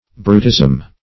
brutism - definition of brutism - synonyms, pronunciation, spelling from Free Dictionary
Search Result for " brutism" : The Collaborative International Dictionary of English v.0.48: Brutism \Bru"tism\, n. The nature or characteristic qualities or actions of a brute; extreme stupidity, or beastly vulgarity.